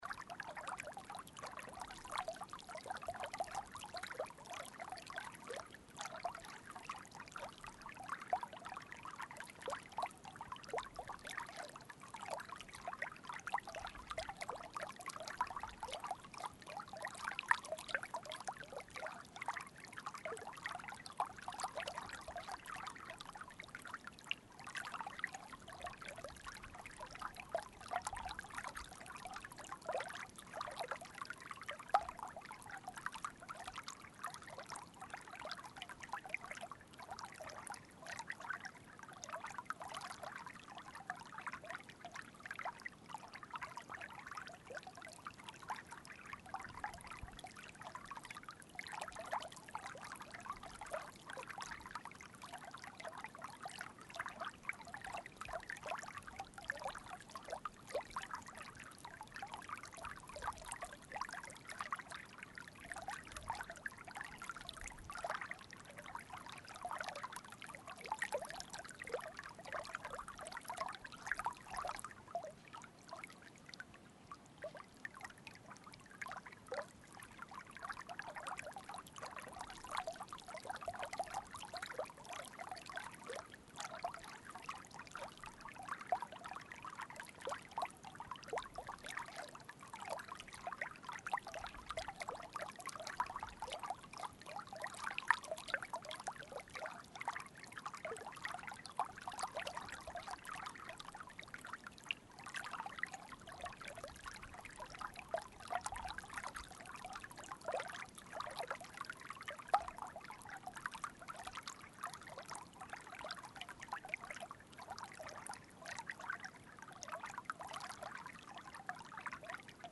Успокаивающий звук воды в аквариуме с плавающими рыбками